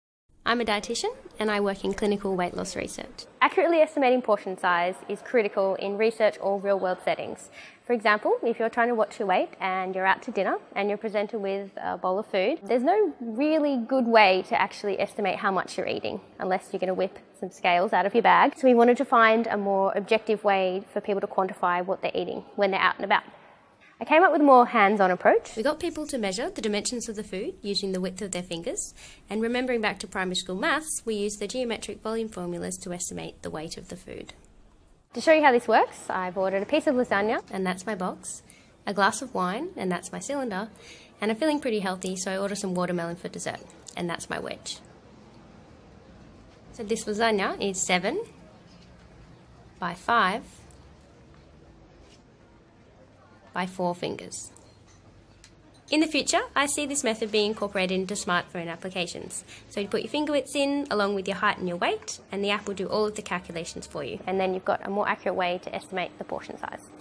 Retell Lecture Measuring Food (RL), Audio